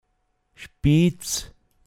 pinzgauer mundart
Spiiz, m. Spitz, Bergspitze